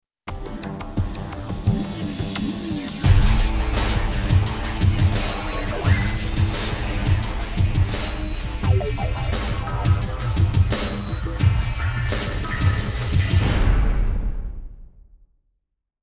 广告片背景音乐